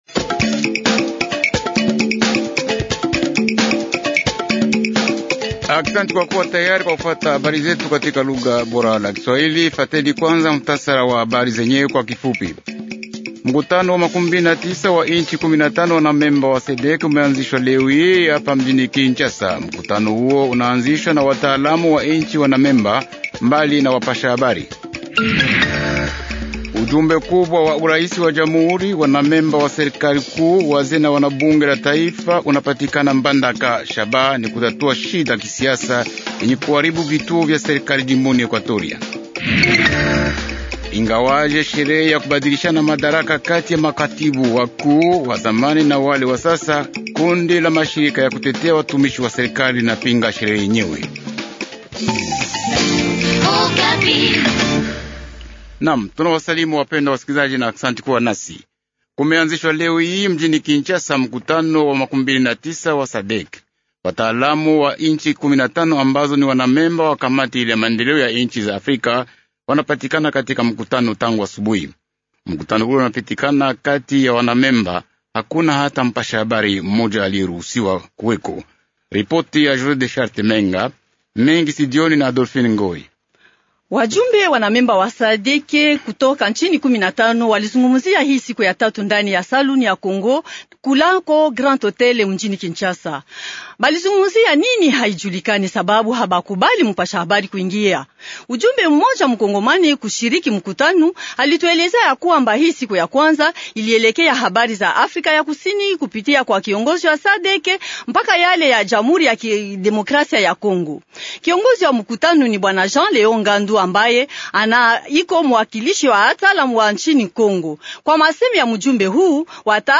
Journal Swahili soir